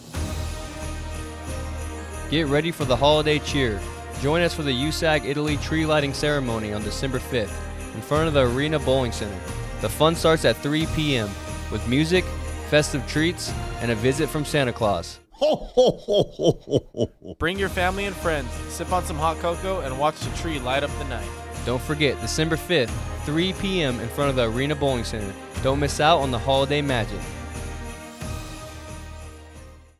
Radio spot- USAG Italy Holiday Tree Lighting Ceremony